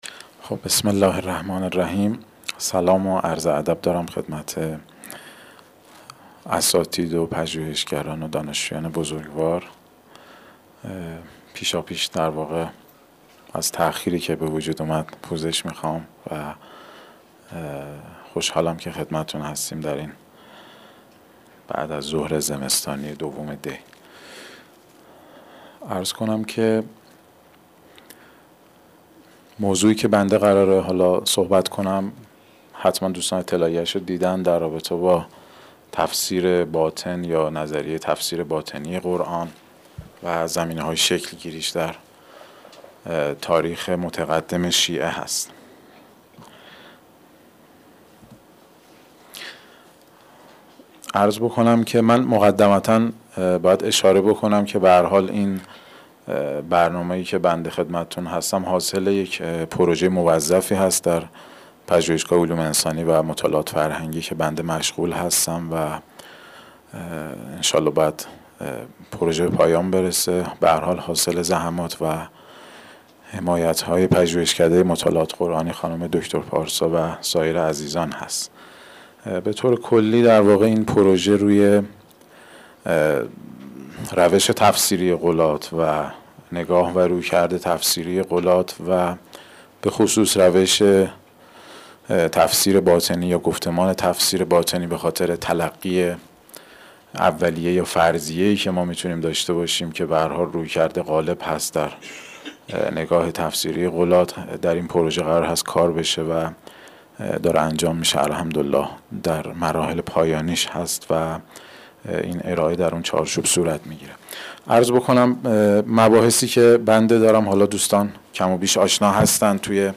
سالن اندیشه